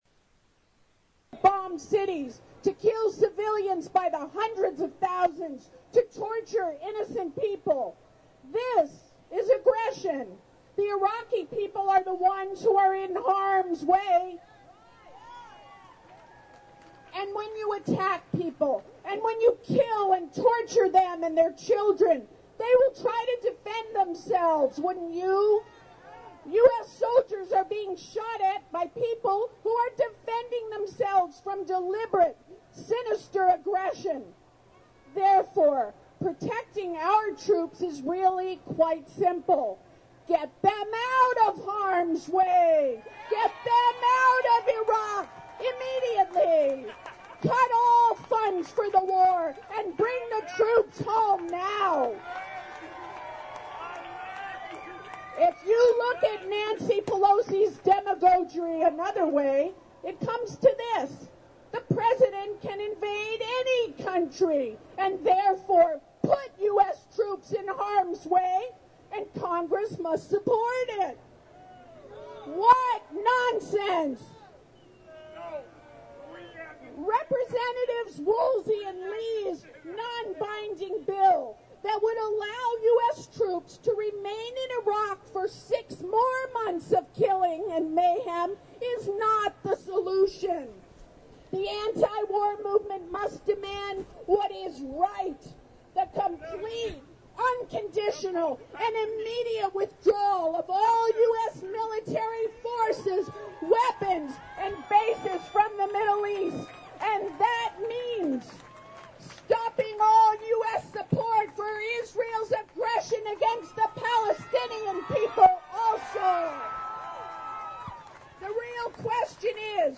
hear speeches from the January 27 San Francisco anti-war rally at Powell/Market Street